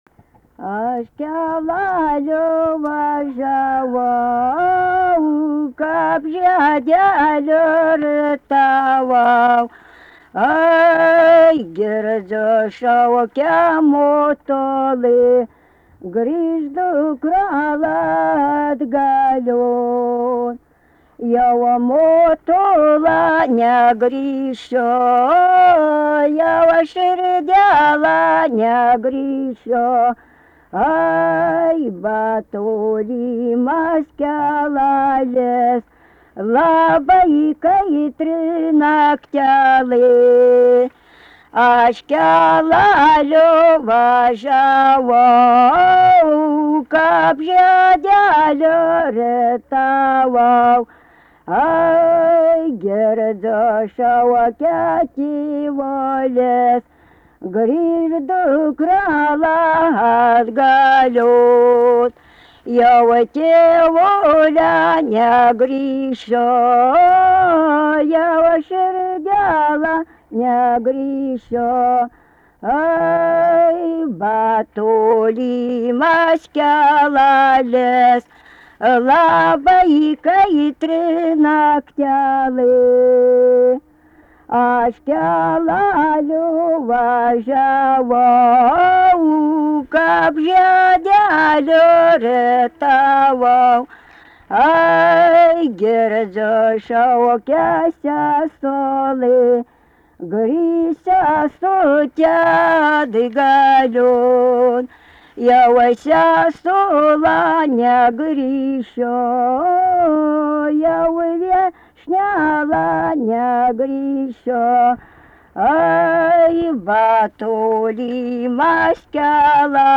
Rudnia
vokalinis
2 balsai